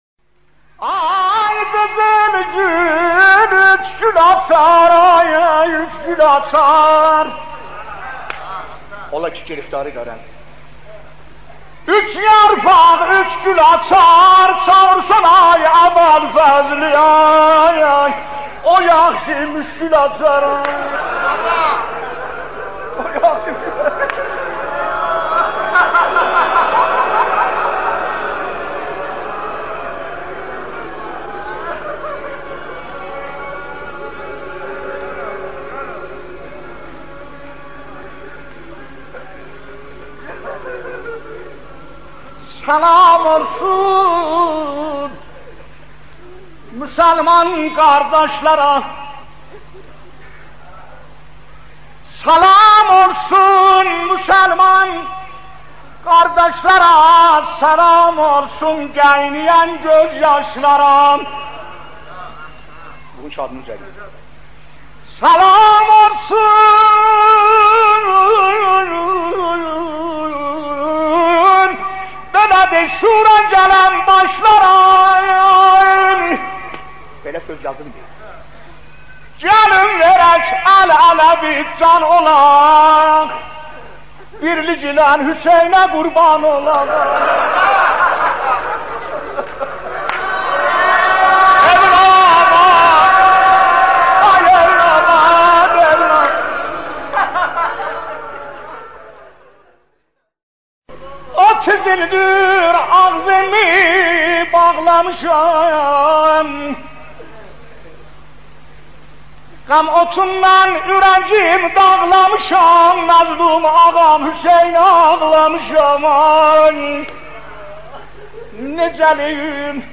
دانلود گلچین نوحه های قدیمی آذری مرحوم حاج سلیم موذن زاده اردبیلی